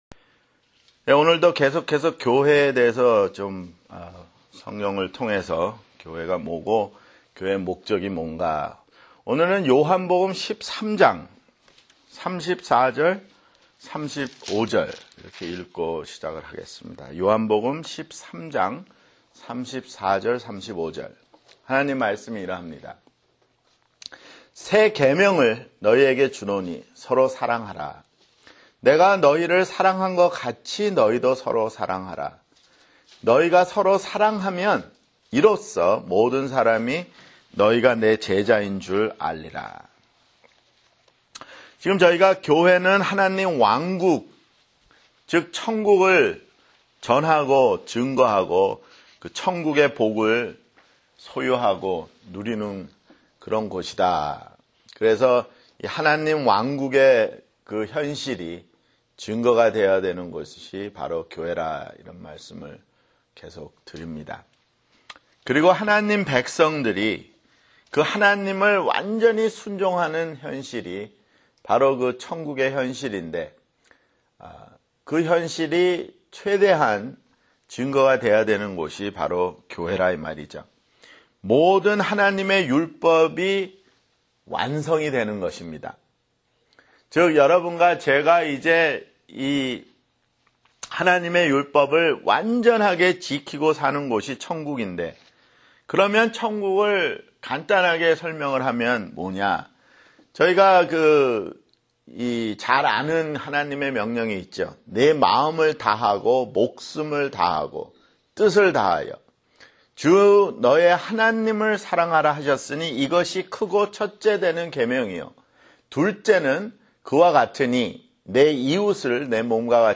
[성경공부] 교회 (8)